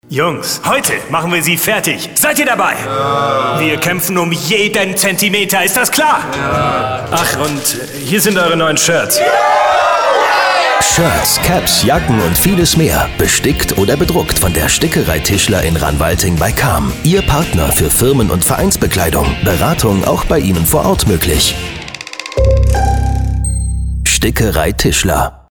Dann haben wir hier noch unseren Radiospot für Sie:
radiowerbung.mp3